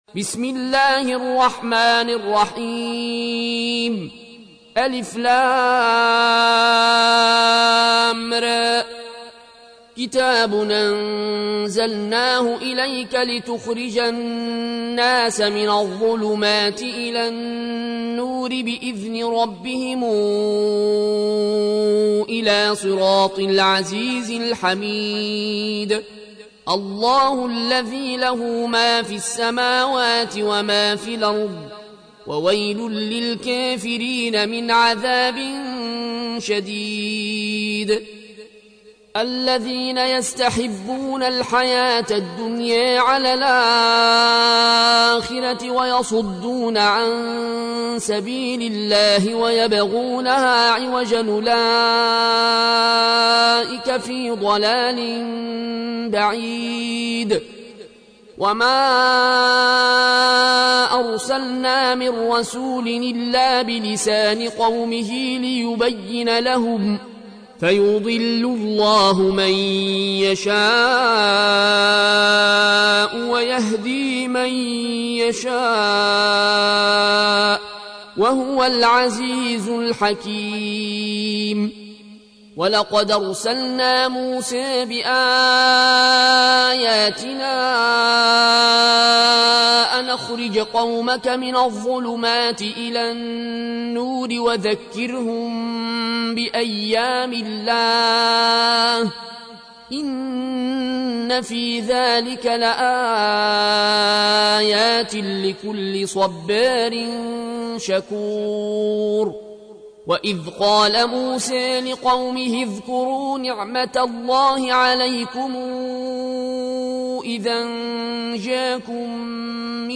تحميل : 14. سورة إبراهيم / القارئ العيون الكوشي / القرآن الكريم / موقع يا حسين